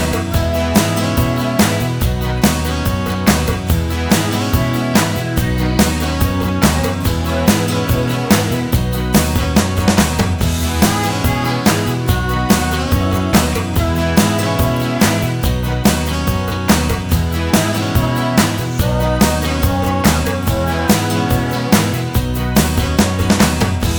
One Semitone Up Country (Male) 4:17 Buy £1.50